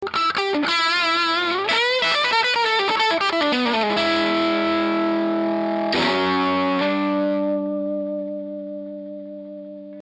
I recorded it using a Lambda pre-box and Shure SM57.
The guitar is a faded les paul double cut with old(ish) P-90's. It is a recent acquisition and sounds very nice.
The guitar was plugged into the bright channel input 2.....the volume was pretty much cranked with the treble on 8, mids on 3-4 and bass on 0.
I Have a Marshall bluesbreaker RI and it does not have the bold strident tone or chunky bottom that the Trinity has.
The SM57 was right at the grillcloth at the Alnico Tone Tubby.